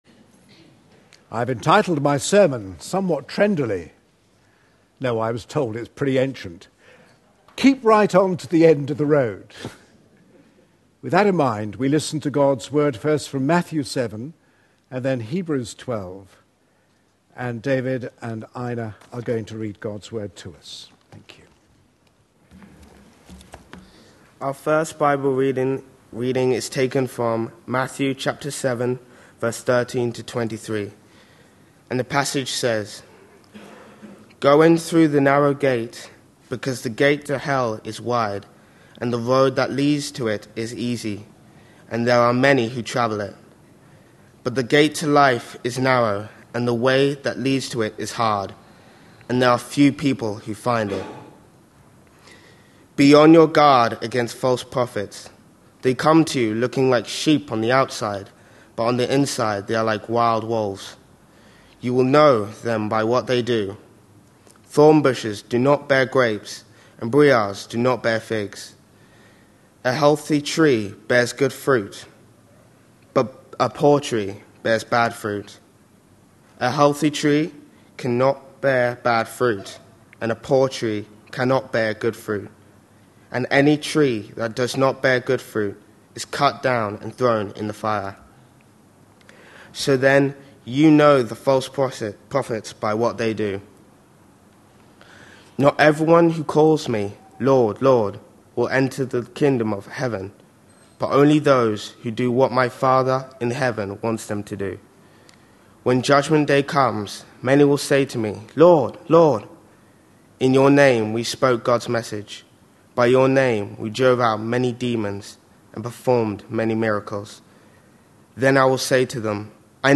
A sermon preached on 22nd July, 2012, as part of our Red Letter Words series.